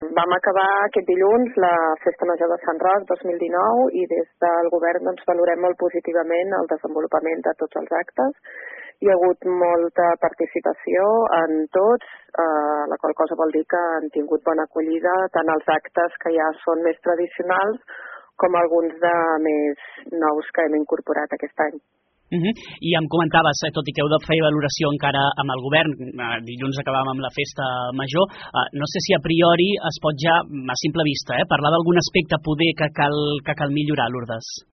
Davant a bona rebuda de les activitats, la regidora de cultura Lurdes Borrell valora com a molt positiva l’edició d’enguany.